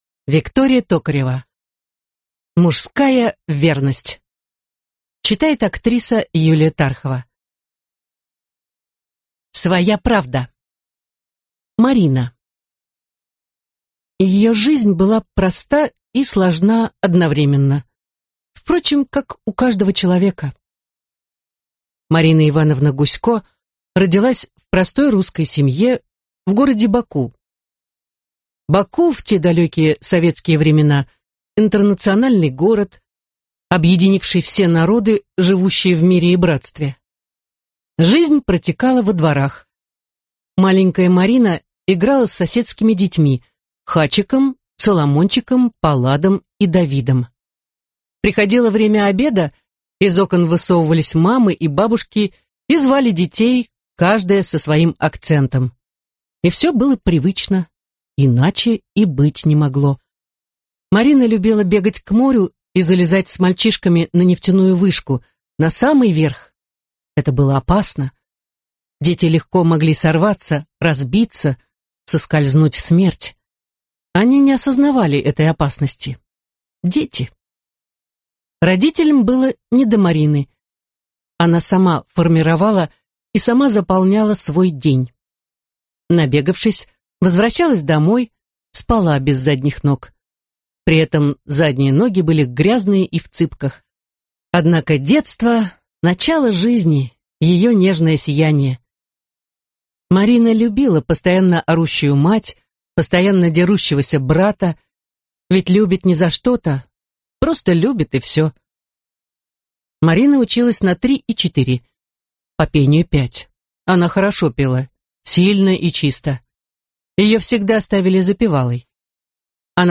Аудиокнига Мужская верность (сборник) | Библиотека аудиокниг